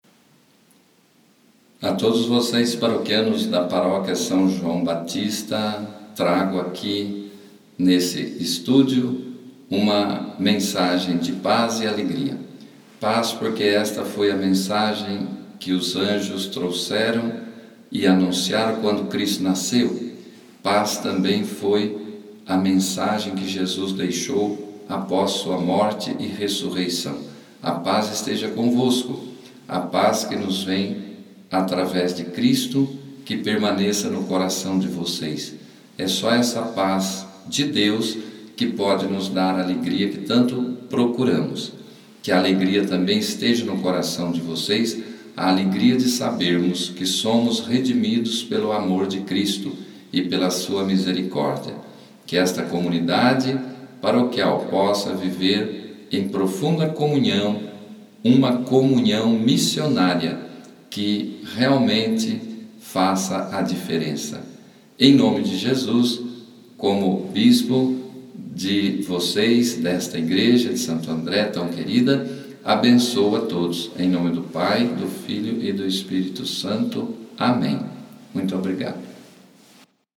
Palavra do Pastor gravada na inauguração do nosso estúdio: